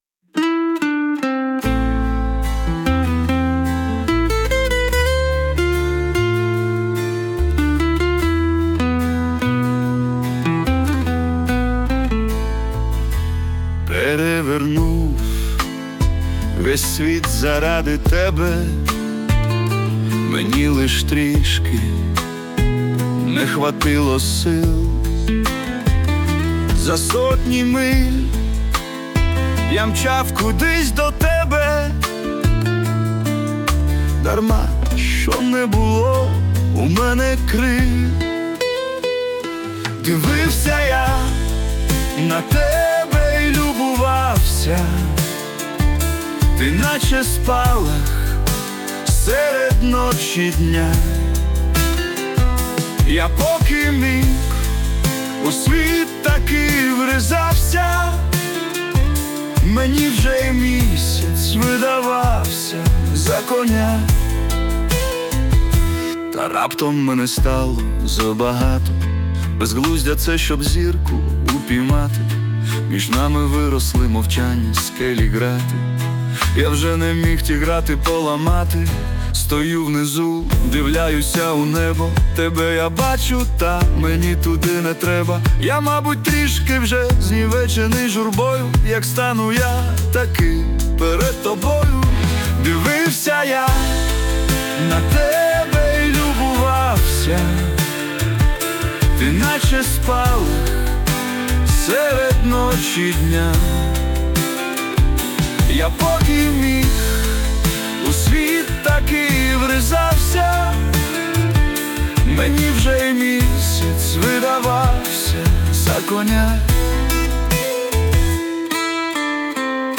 Музичний супровід із застосуванням ШІ
СТИЛЬОВІ ЖАНРИ: Ліричний